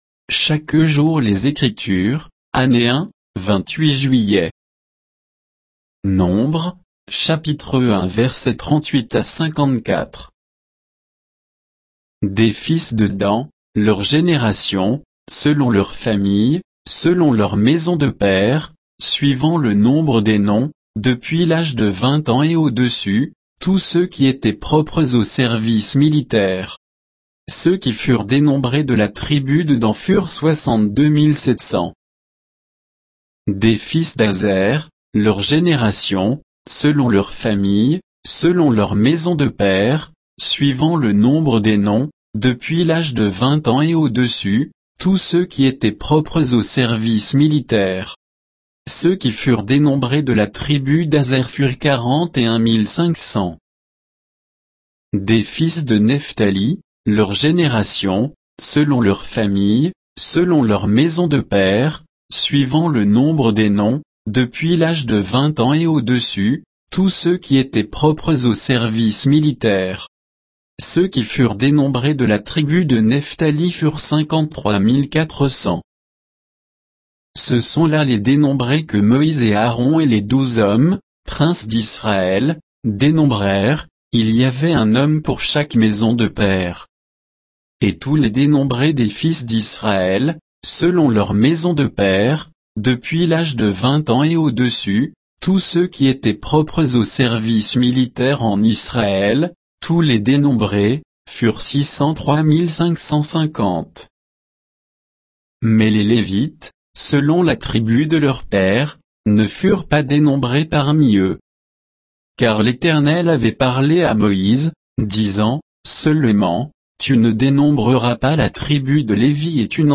Méditation quoditienne de Chaque jour les Écritures sur Nombres 1